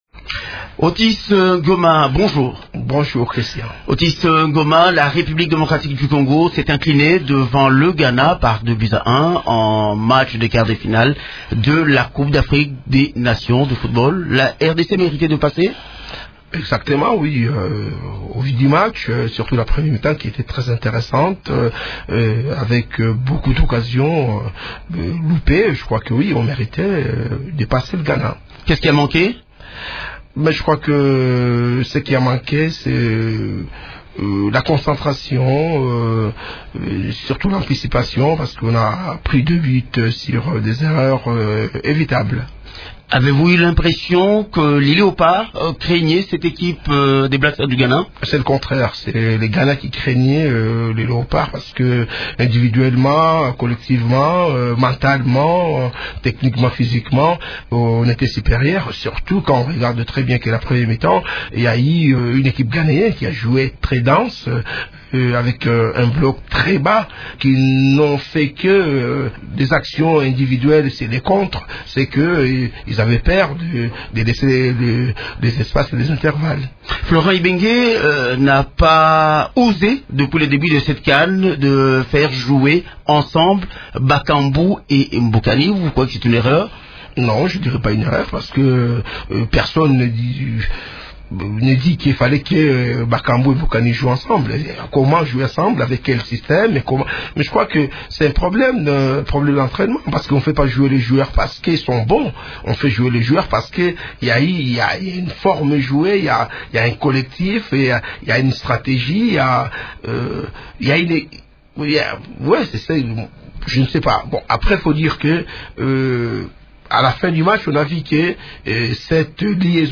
Nous avons interrogé sur l’ancien sélectionneur après l’élimination des Léopards.